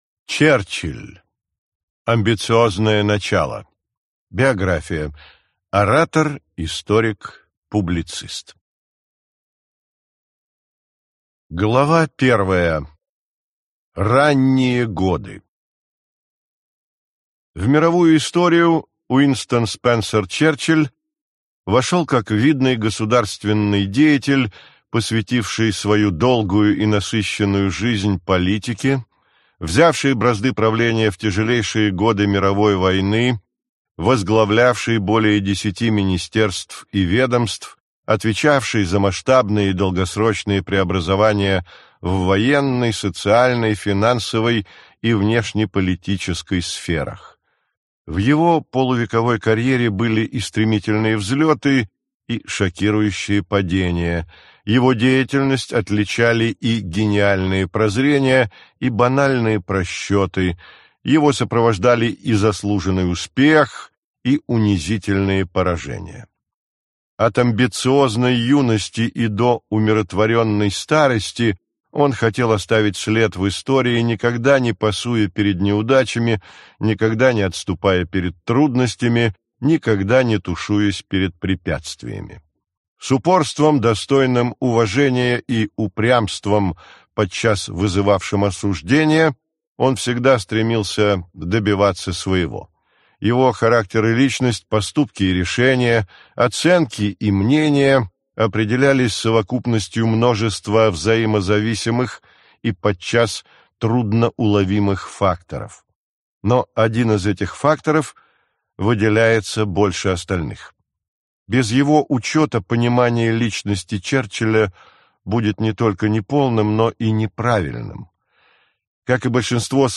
Аудиокнига Черчилль. Биография. Часть 1 – Ранние годы | Библиотека аудиокниг